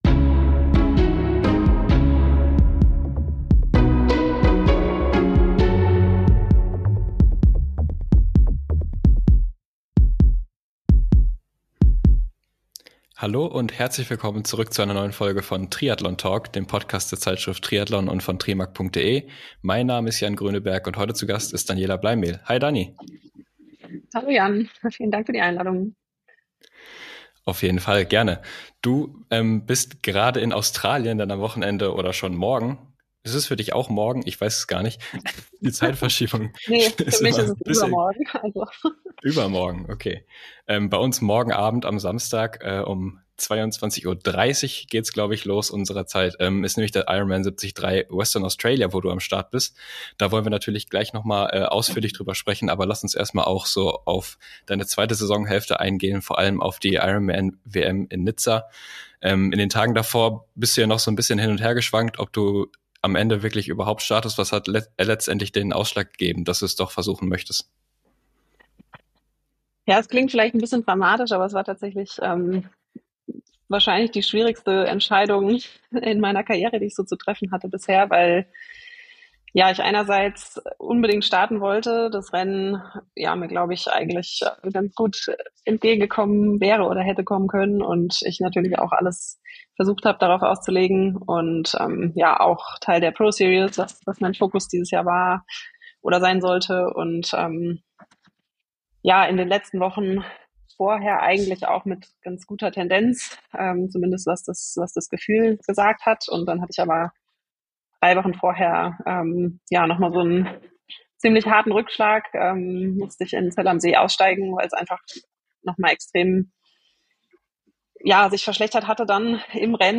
Zwei Experten aus der Redaktion sprechen über das aktuelle Triathlongeschehen.